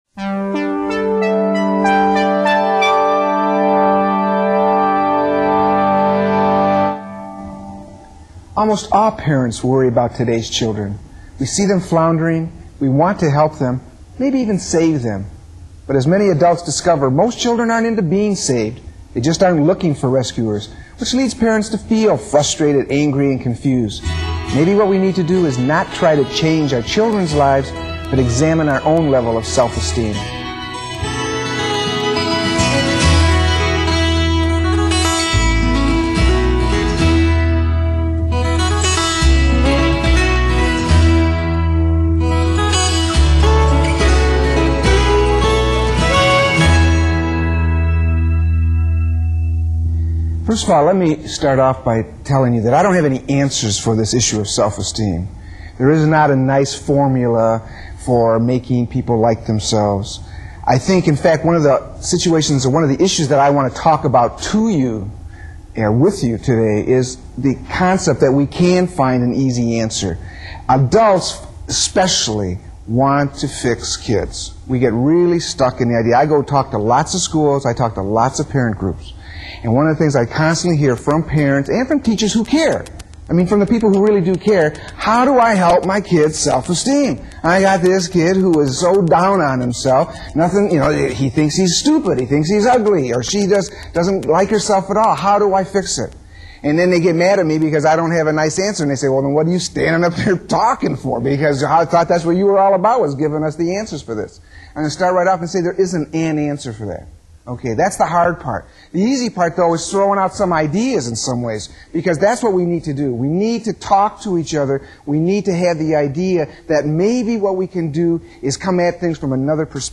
Parents and Self-Esteem Audio (Public Televison Lecture) (Digital)
This is the audio file of one of my talks in my six part series for Ohio Public Television called “Who’s In Charge”. This one is focused on adults, especially parents, and how they look at self-esteem for themselves. The point is that you can’t help your child build his or her self-esteem if you don’t have a good one yourself and don’t know how you even arrive at that conclusion.